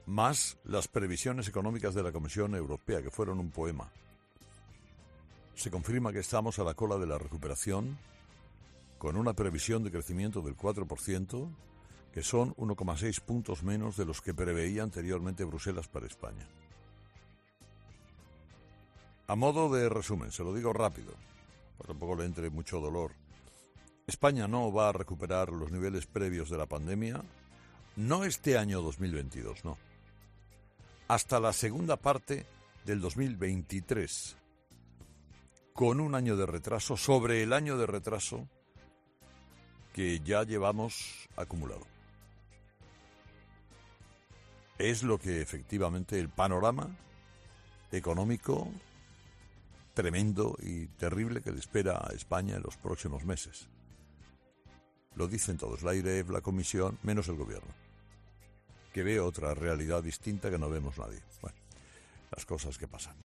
Una estimación de la que Carlos Herrera se ha hecho eco en su editorial para explicar a sus oyentes la verdadera situación económica en la que nos encontraremos en los próximos meses.